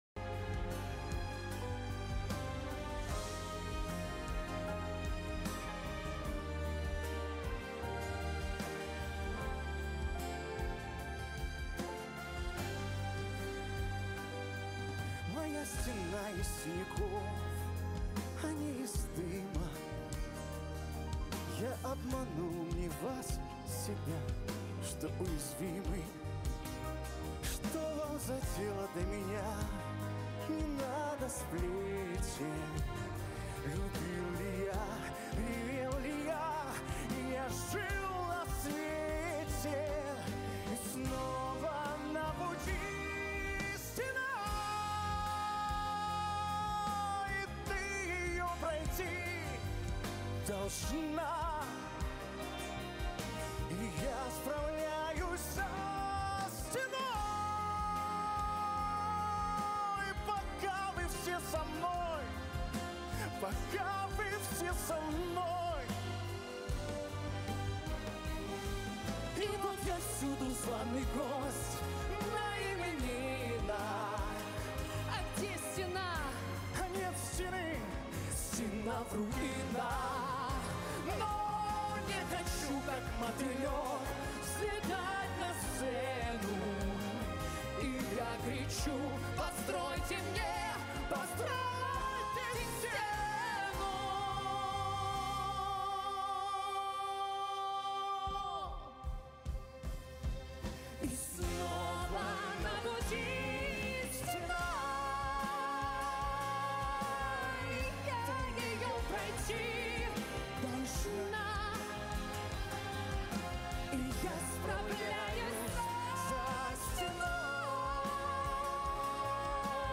Гала-концерт от 06.12.2024г.